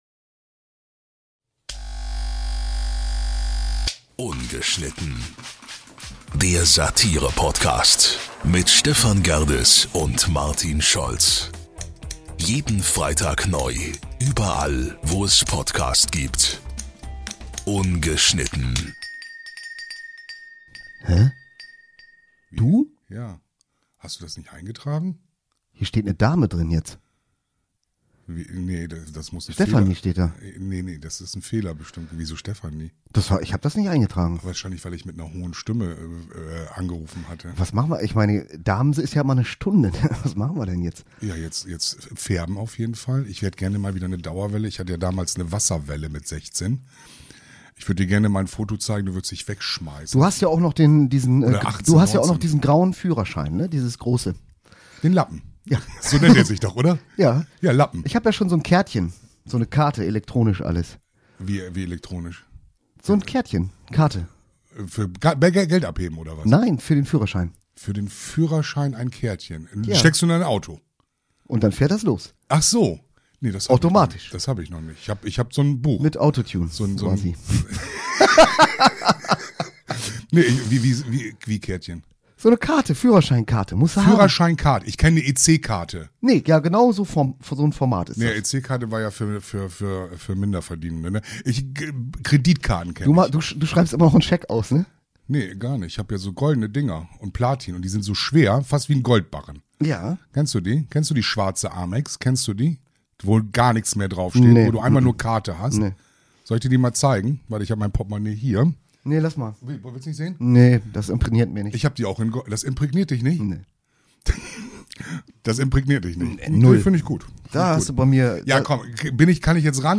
Natürlich auch mit viel Musik und Erinnerungen an die 90er.